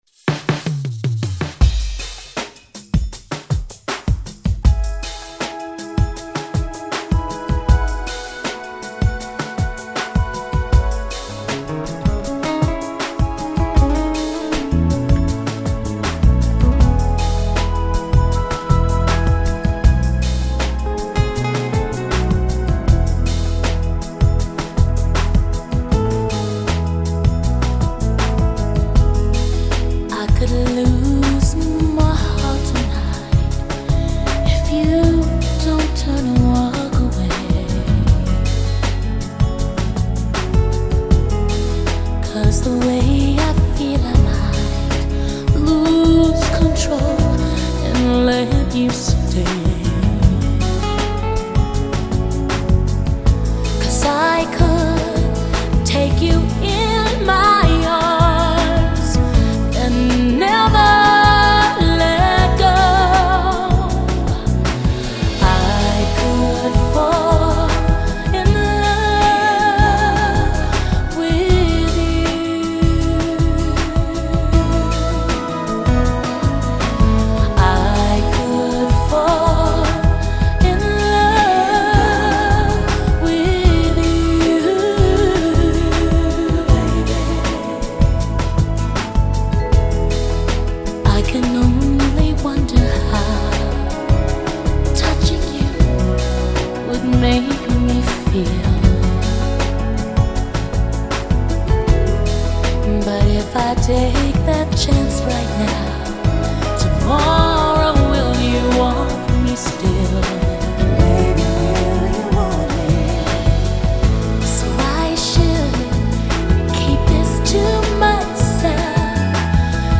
texmex